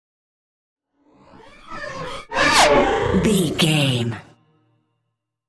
Sci fi vehicle pass by super fast
Sound Effects
futuristic
pass by
vehicle